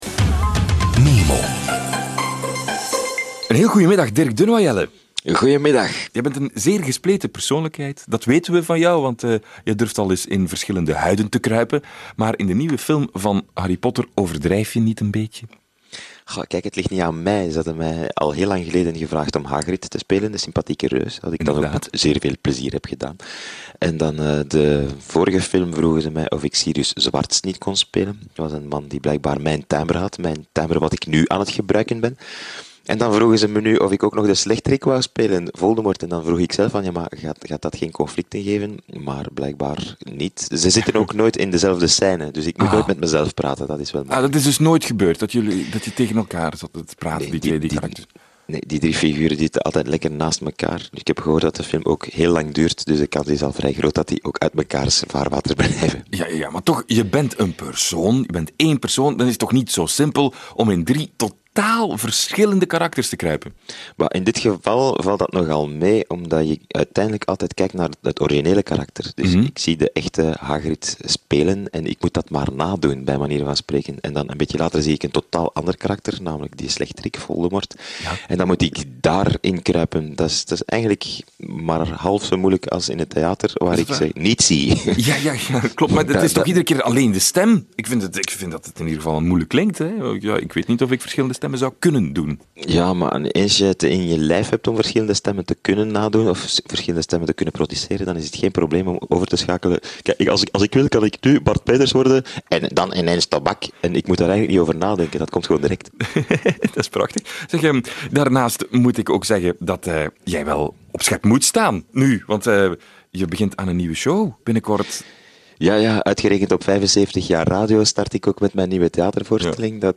Ook de single kwam aan bod. Schrik niet als je in het liedje ineens een "knip" hoort: we gaan onze hit natuurlijk niet integraal via het net weggeven, he !